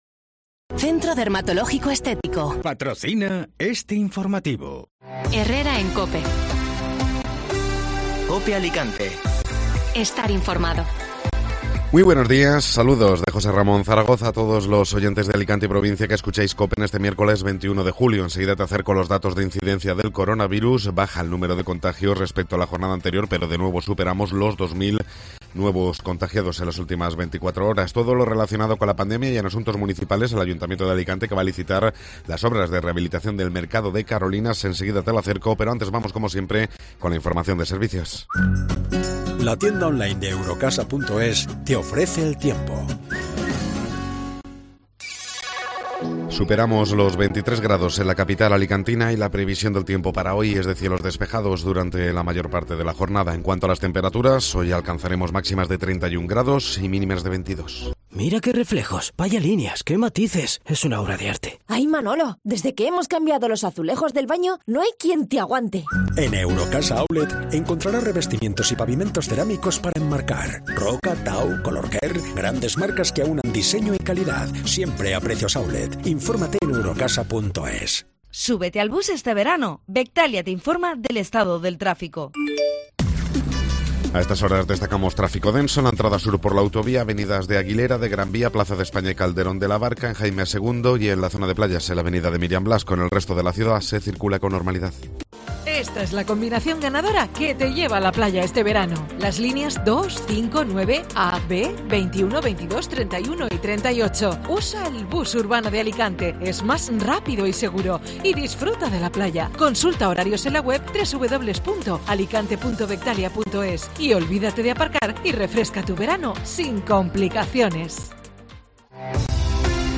Informativo Matinal (Miércoles 21 de Julio)